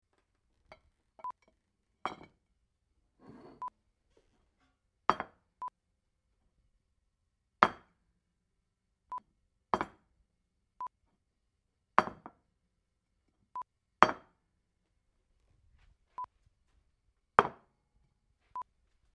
Bowl Or Plate On Kitchen Surface (sound fx)
China, clay, bowl or plate being placed, put down on a hard kitchen surface. Could be used as a cup or mug being placed on a hard surface.
BowlOnSurface2_plip.mp3